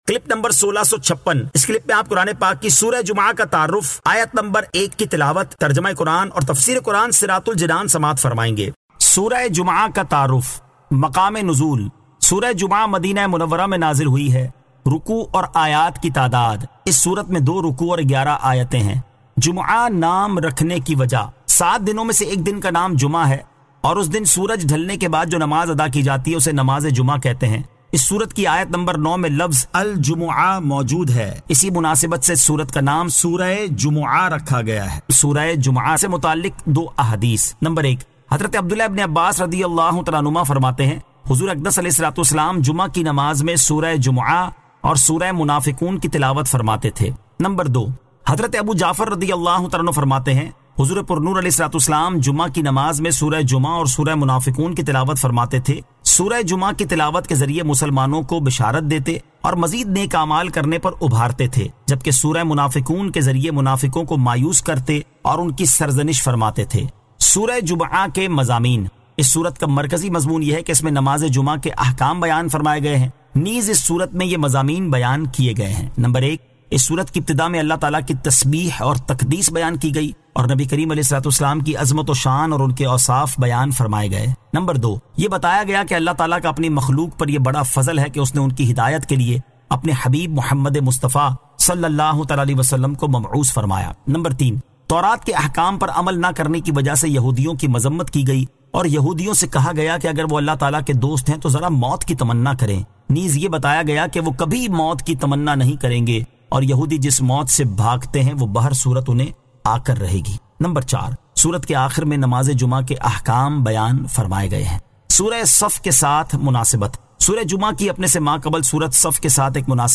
Surah Al-Jumu'ah 01 To 01 Tilawat , Tarjama , Tafseer